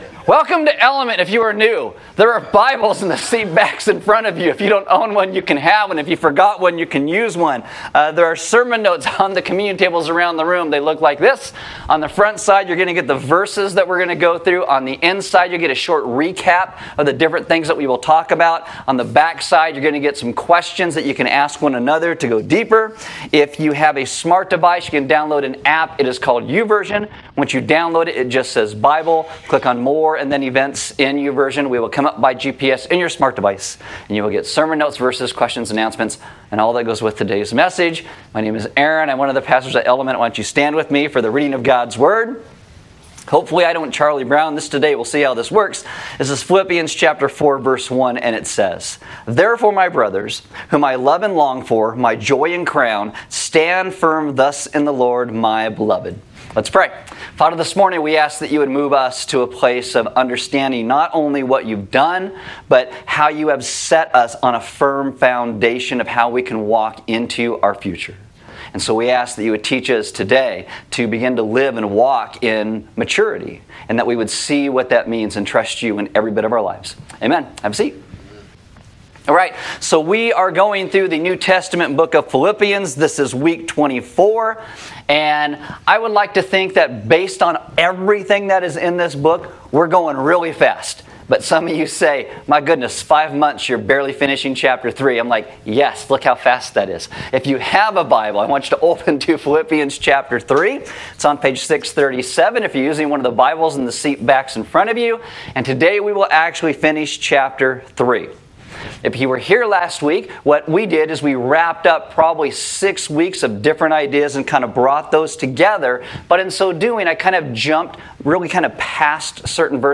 Message Archive - Element Christian Church